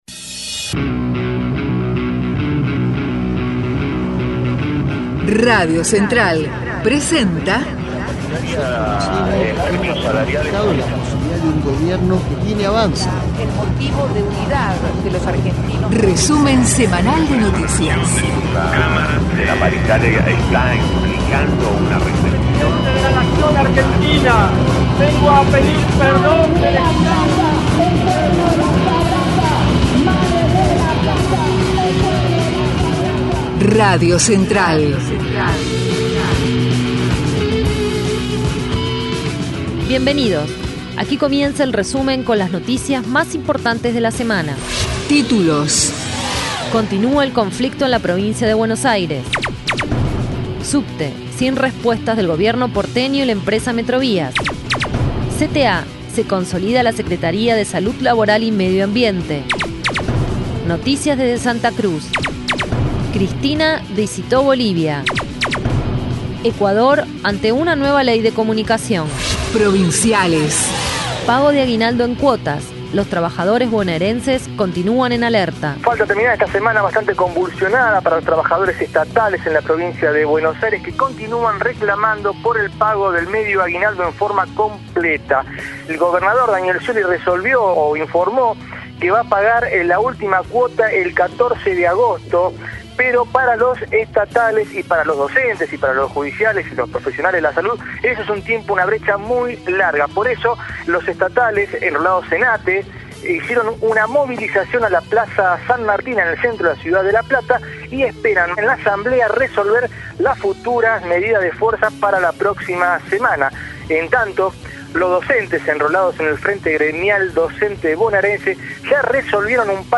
Resumen Semanal de Noticias Nro 13